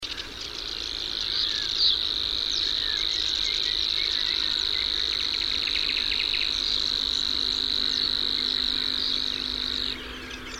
Ruokosirkkalintu / Savi's Warbler (Locustella luscinioides)
20.5.2005 Espoo, Laajalahti, Finland (mp3, 104 KB) Laulu / Song